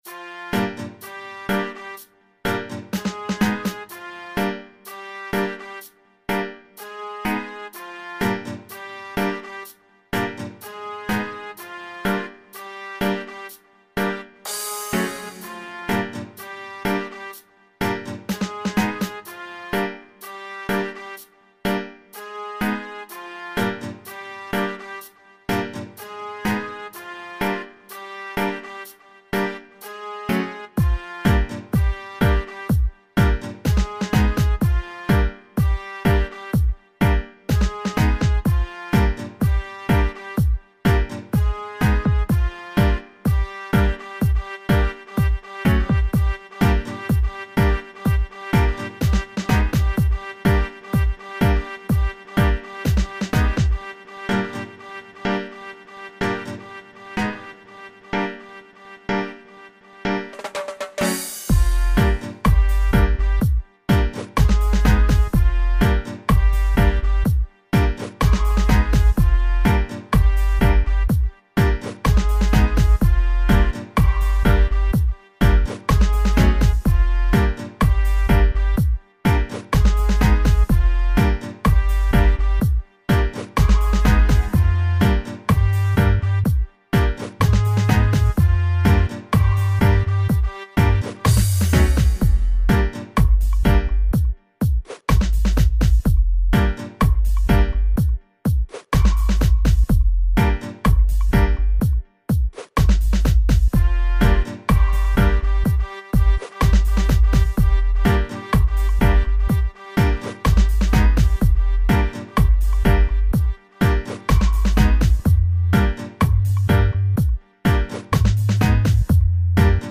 Tune in process. If you want to have a dub for soundsystem test feel free to contact me.
hunter-dub-v1_preview-mp3-6hkoy